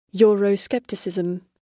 euroscepticism_oxdic.mp3